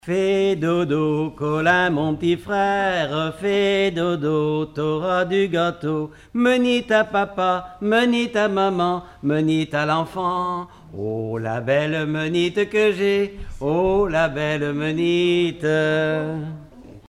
formulette enfantine : jeu des doigts
Collectif-veillée (1ère prise de son)
Pièce musicale inédite